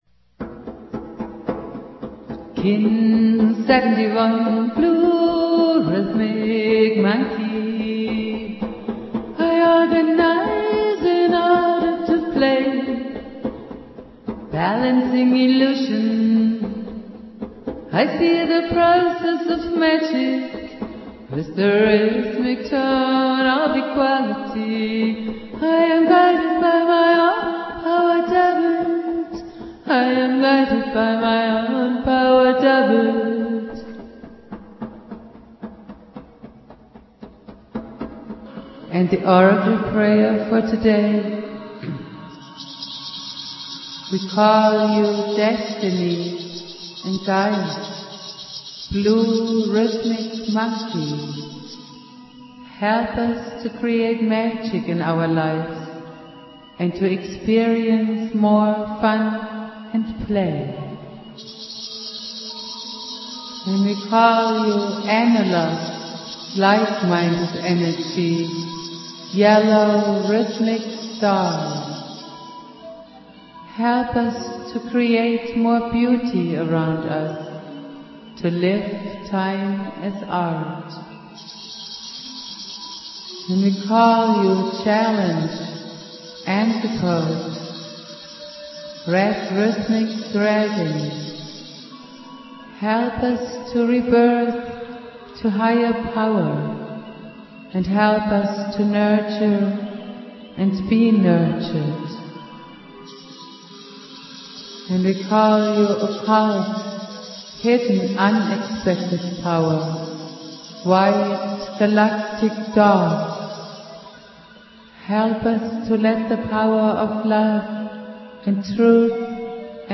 Prayer
Jose's spirit and teachings go on Jose Argüelles playing flute.